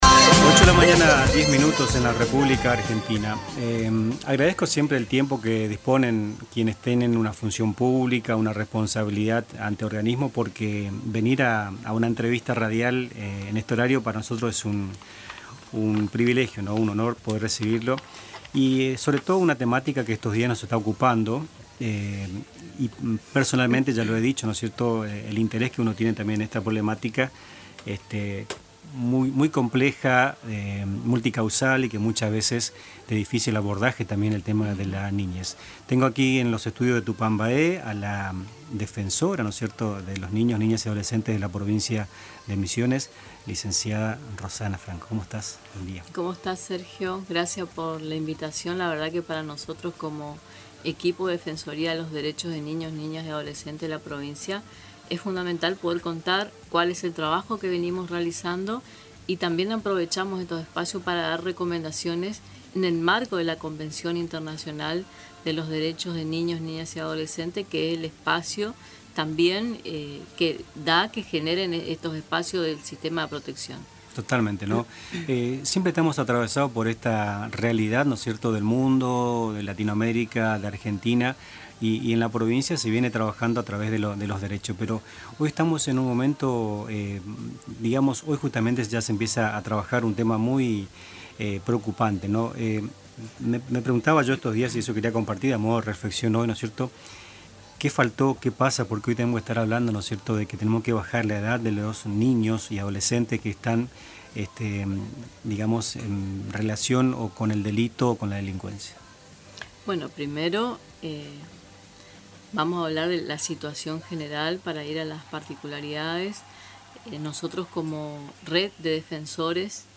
Rossana Franco, Defensora de los Derechos de Niños, Niñas y Adolescentes de Misiones, afirmó en una entrevista en Nuestras Mañanas que reducir la edad de imputabilidad penal no resuelve los problemas de los menores en conflicto con la ley.